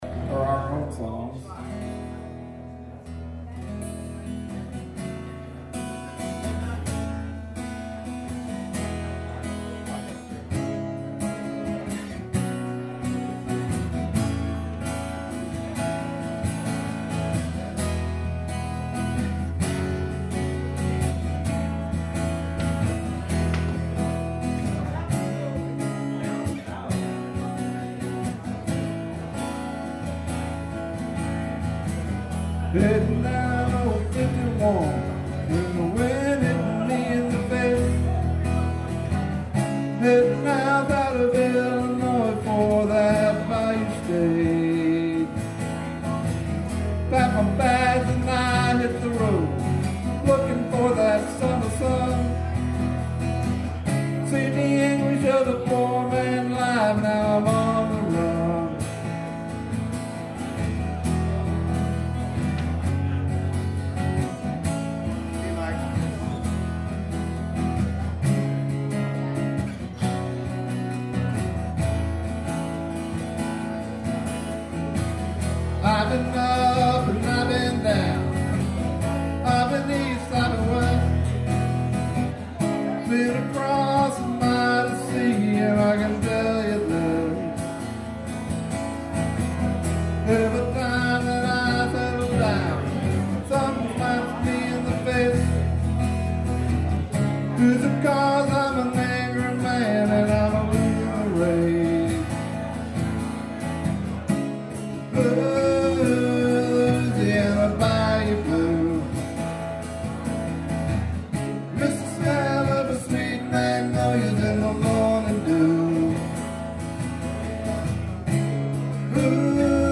Guitar/Mandolin/Vox
Guitar/Bass/Vox
Harmonica/Vox
Banjo/Guitar/Vox